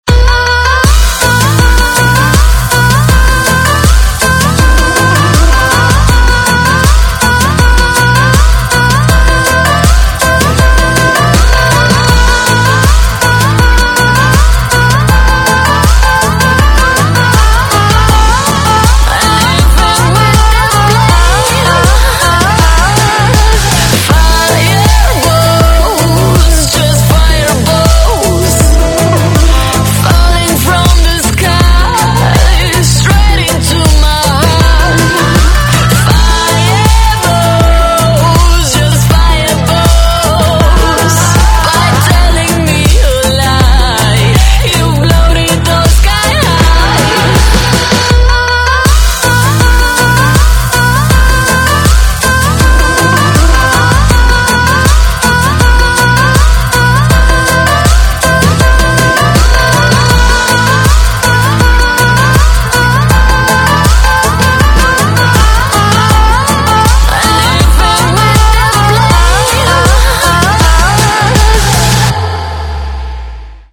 • Качество: 320, Stereo
поп
красивые
женский вокал
Electronic
спокойные
Удивительная музыка с потрясающим вокалом певицы